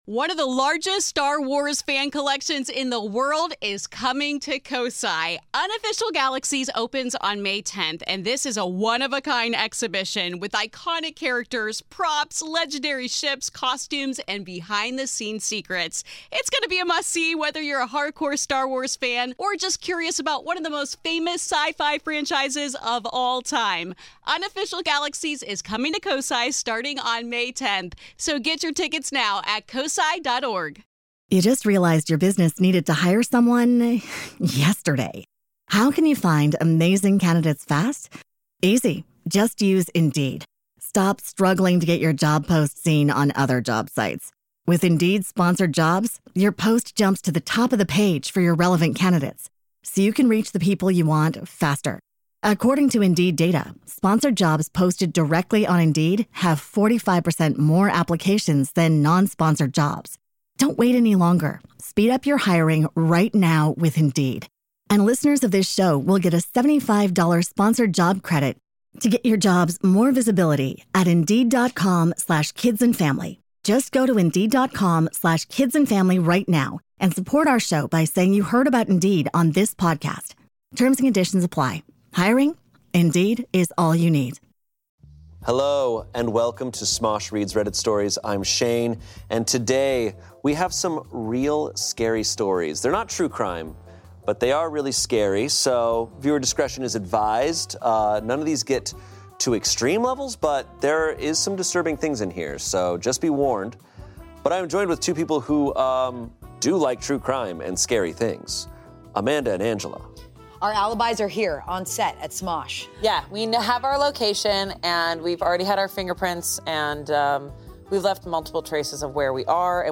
I Do NOT Feel Safe | Reading Reddit Stories